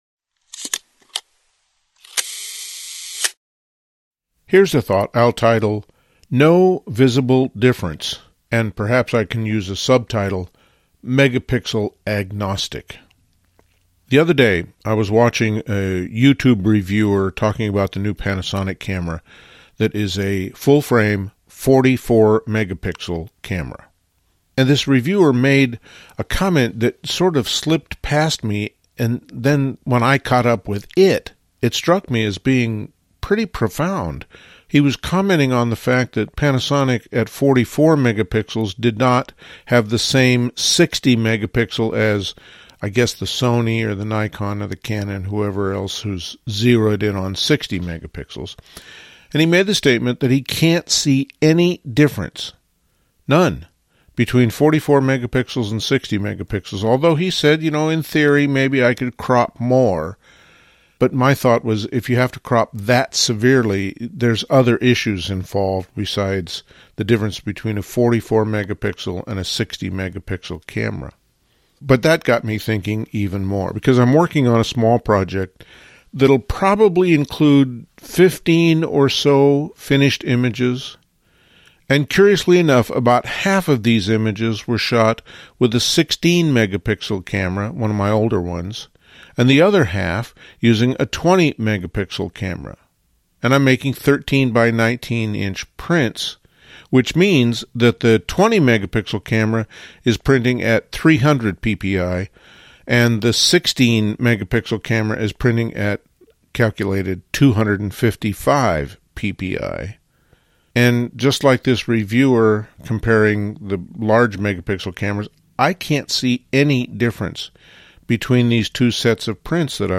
These talks focus on the creative process in fine art photography.
Included in this RSS Feed are the LensWork Podcasts — posted weekly, typically 10-20 minutes exploring a topic a bit more deeply — and our almost daily Here's a thought… audios (extracted from the videos.)